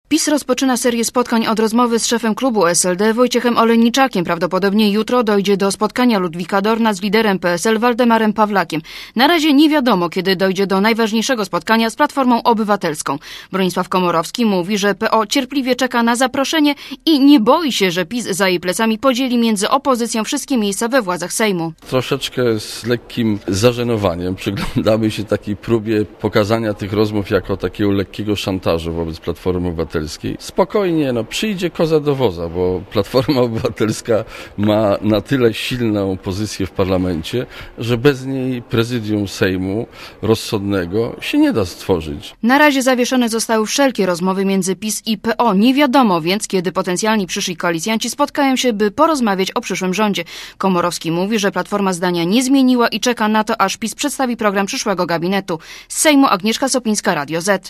Źródło: RadioZet Relacja reportera Radia ZET Oceń jakość naszego artykułu: Twoja opinia pozwala nam tworzyć lepsze treści.